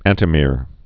(ăntĭ-mîr)